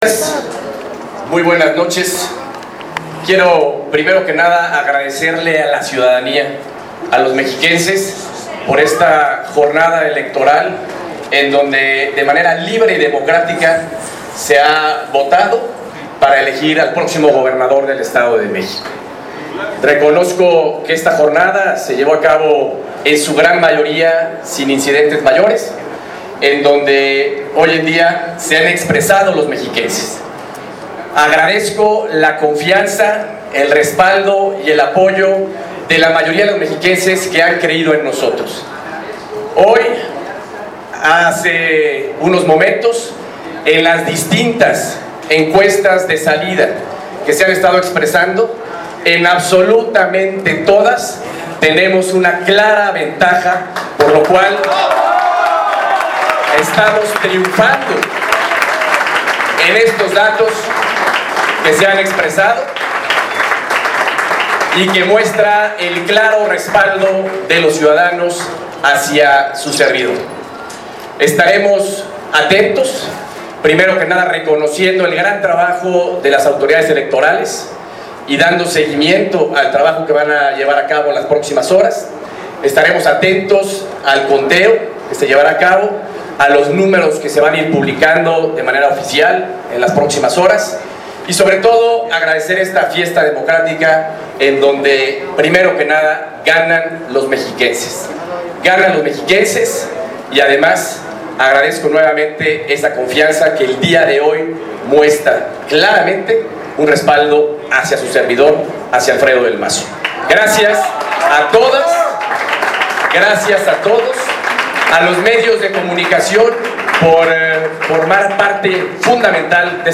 MENSAJE ADM AL SABER SU TRIUNFO
Muchas gracias, muy buenas noches, quiero primero que nada agradecerle a la ciudadanía, a los mexiquenses, por esta jornada electoral en donde de manera libre y democrática se ha votado para elegir al próximo gobernador del Estado de México, dijo Alfredo del Mazo Maza, al presentarse ante los medios de comunicación, luego del cierre de casillas electorales este domingo.